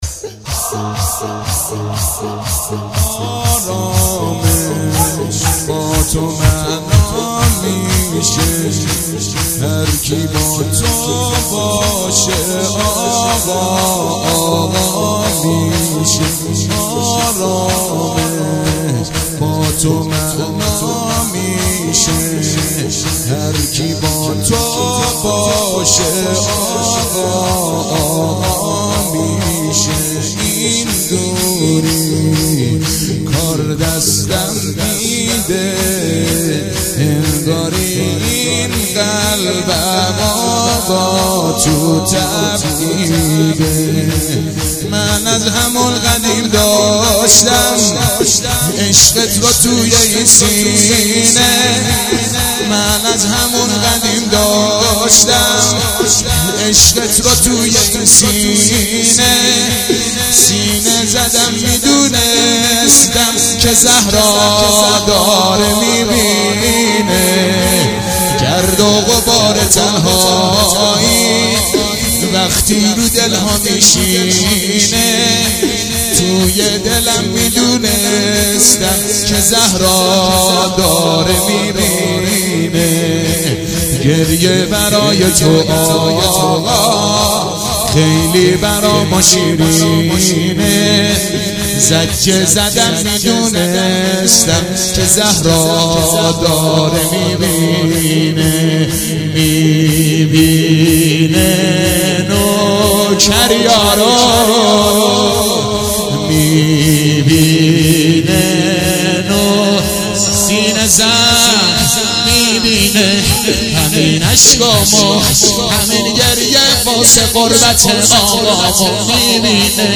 متن شور احساسی امام حسین
هیئت محفل دلدادگان حضرت اباالفضل مشهد